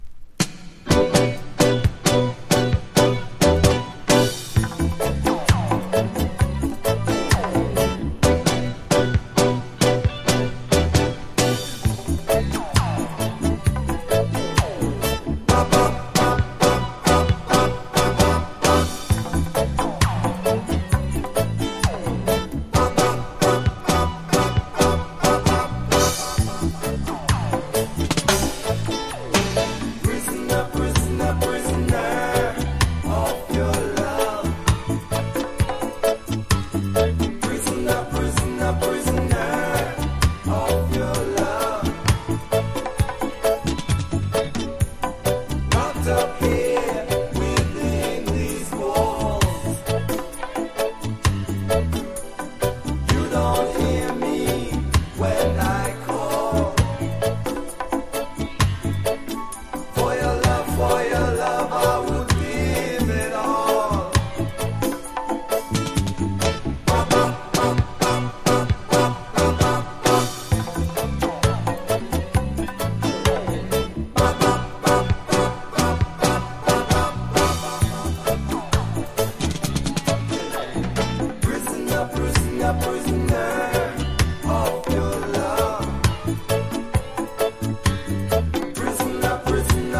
• REGGAE-SKA
謎にスペーシーな効果音と歯切れよいパーカッションが絶妙
# ROOTS